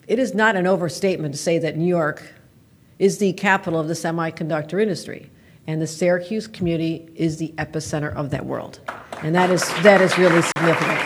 Governor Katy Hochul was in Syracuse today to discuss Micron coming to the Syracuse area.